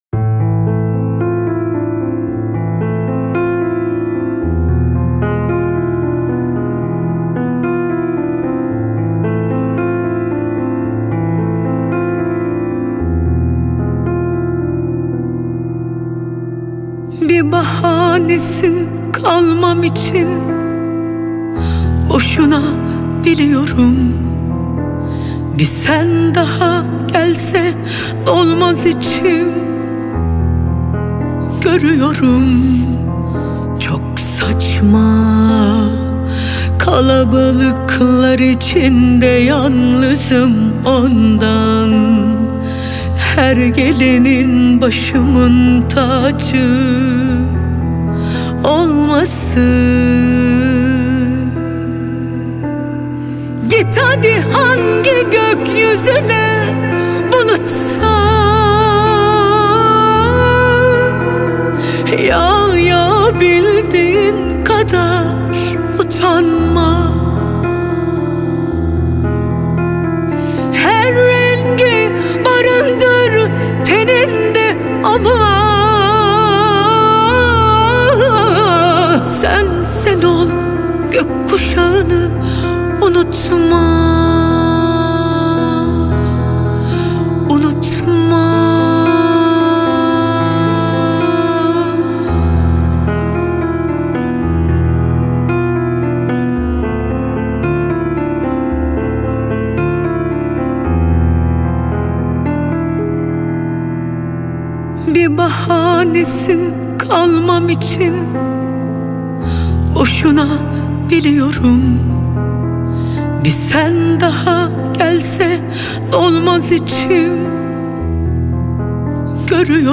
Turkish Pop, Traditional